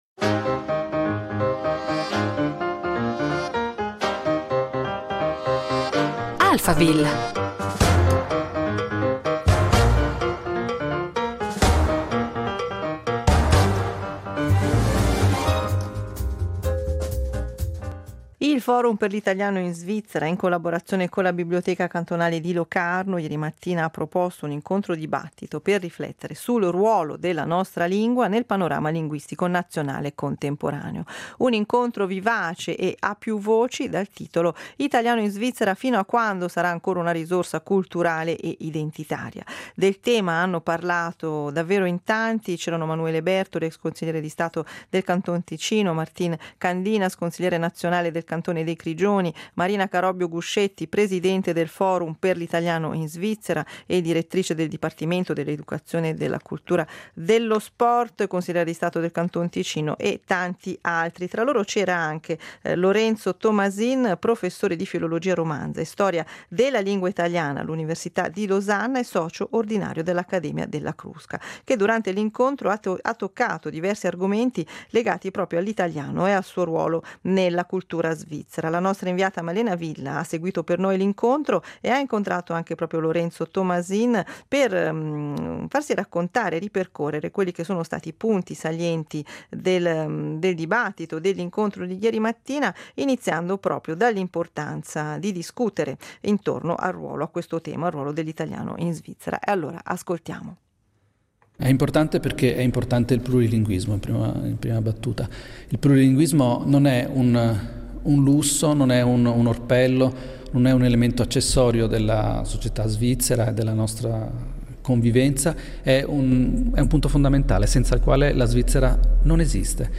Un incontro alla Biblioteca cantonale di Locarno sul ruolo culturale dell’italiano nella Svizzera presente e futura